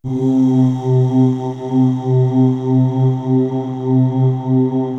Index of /90_sSampleCDs/Best Service ProSamples vol.55 - Retro Sampler [AKAI] 1CD/Partition C/CHOIR UHH